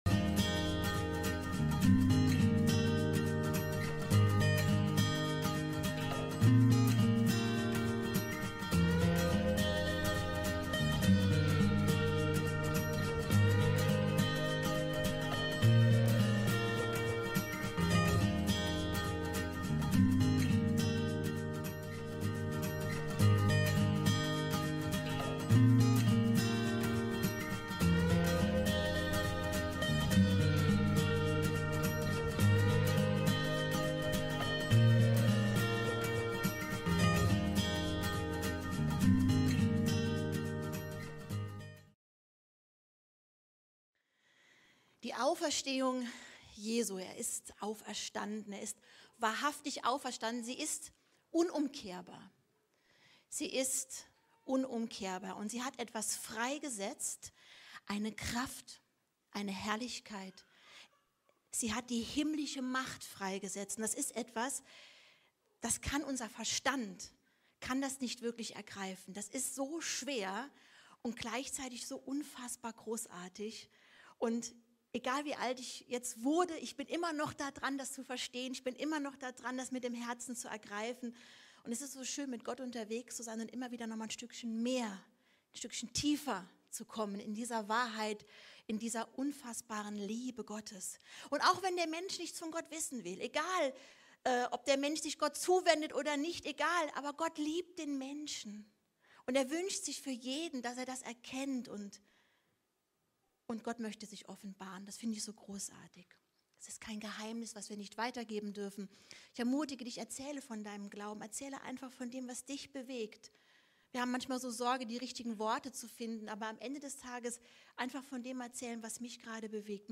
Apostelgeschichte - Teil 1 Himmelfahrt - Predigten Christusgemeinde Gau-Algesheim